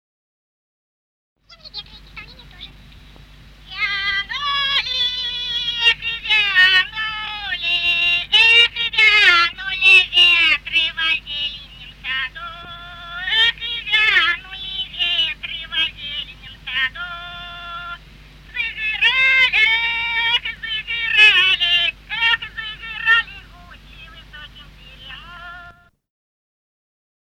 Русские народные песни Владимирской области [[Описание файла::23. Вянули ветры во зеленом саду (свадебная) с. Коровники Суздальского района Владимирской области.